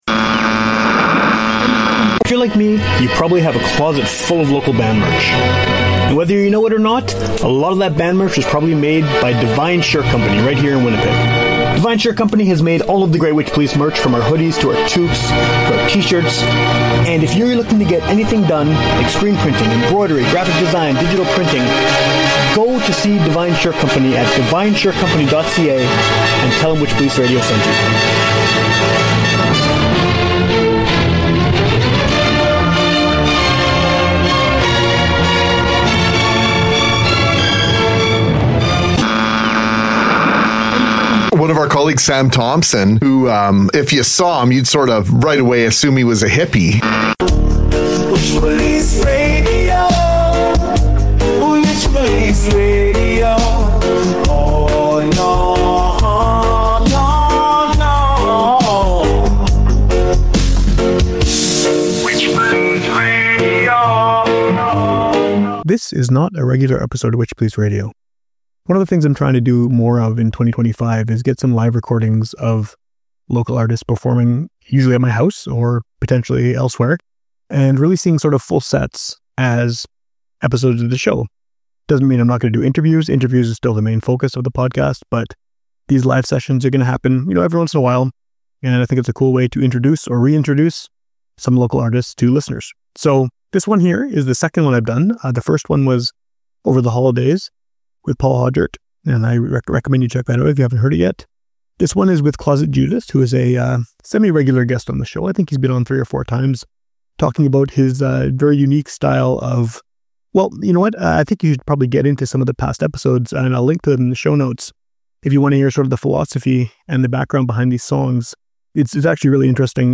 alt-rock singer-songwriter